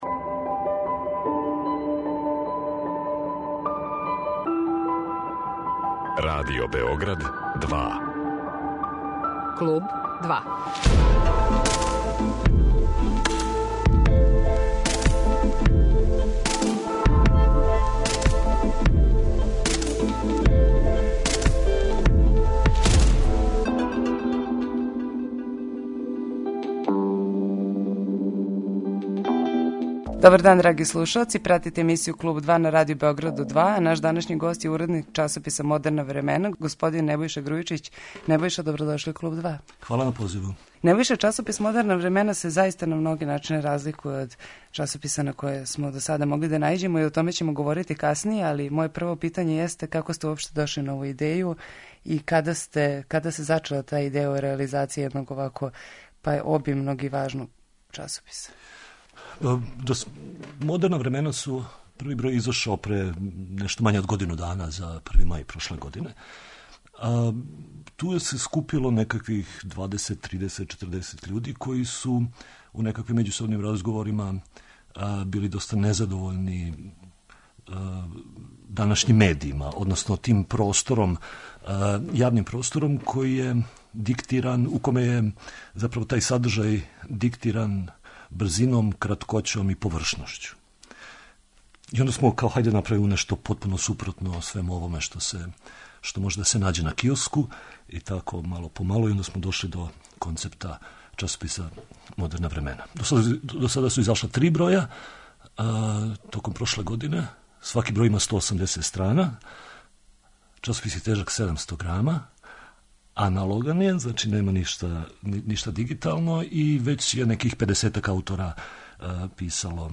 Гост